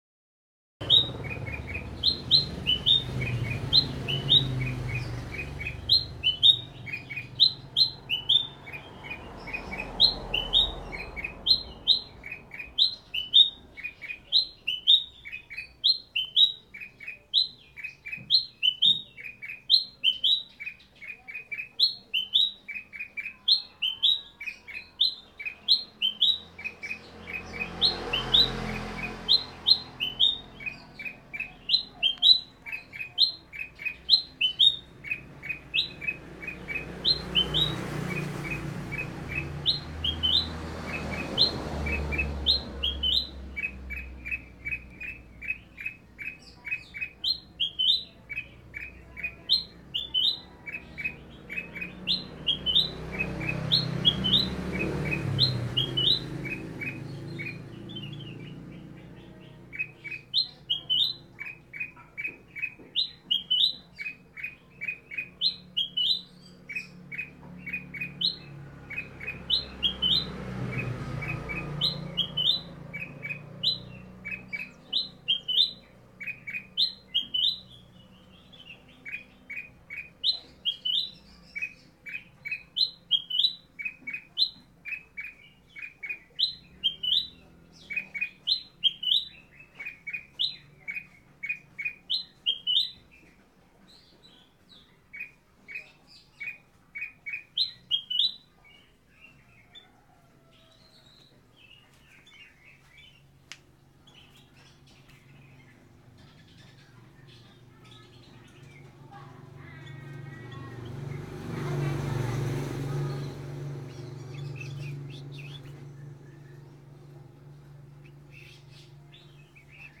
Kumpulan suara burung kutilang liar gacor untuk masteran atau isian dan untuk memikat memanggil burung jantan jenis lokal bahkan kutilang emas dll
Download Suara Kutilang Mp3